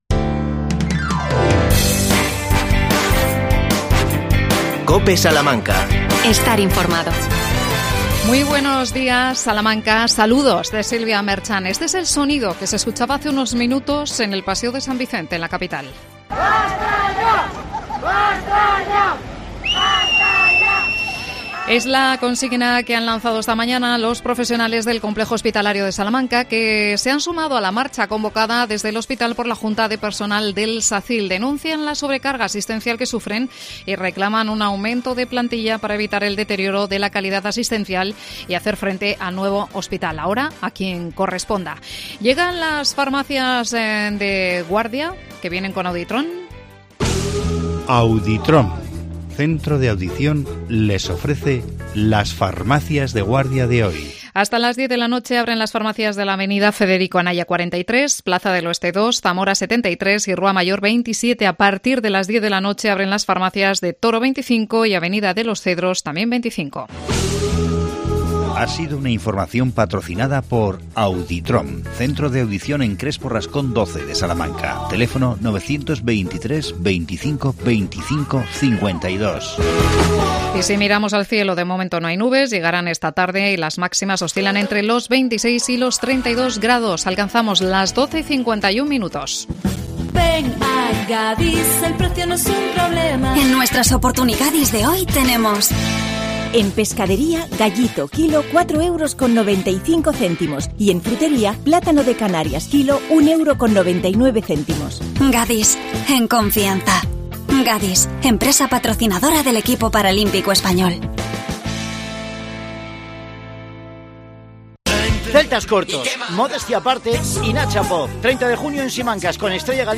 Isabel Macías, concejala de Mayores, habla del programa de Balneoterapia y del programa de Desarrollo Cognitivo junto con la Pontificia.